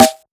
• Good Snare Single Hit D# Key 14.wav
Royality free steel snare drum sound tuned to the D# note. Loudest frequency: 2281Hz
good-snare-single-hit-d-sharp-key-14-Ww5.wav